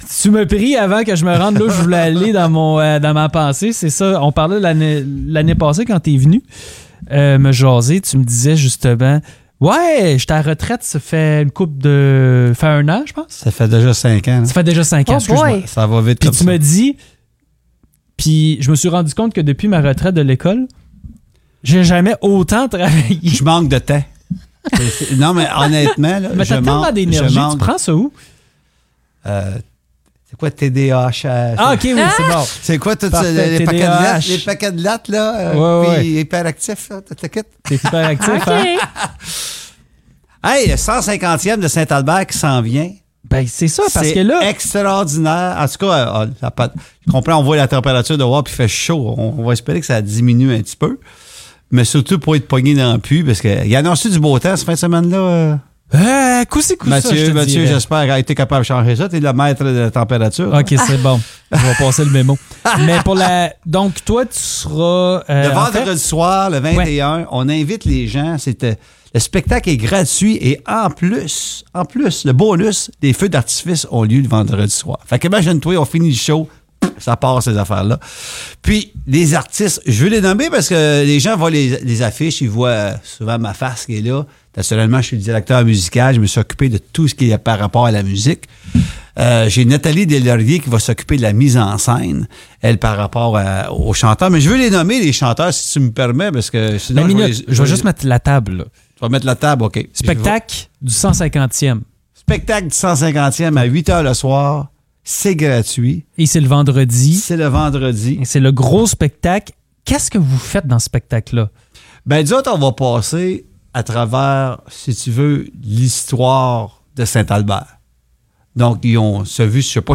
est avec nous en studio.